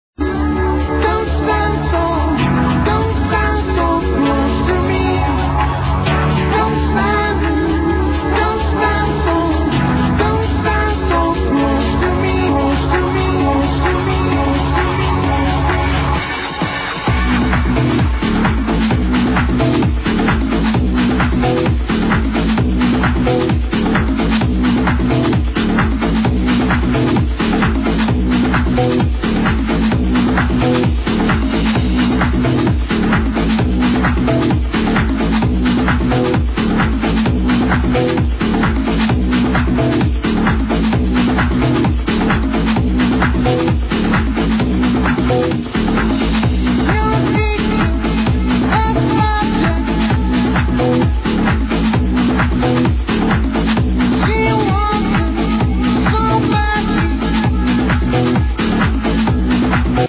Its a remix of